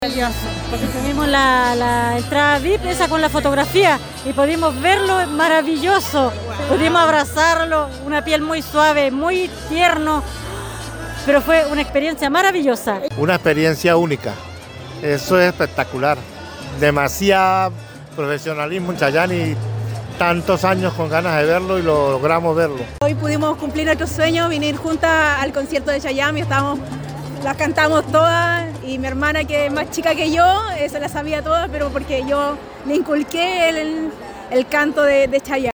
Reacciones de los fanáticos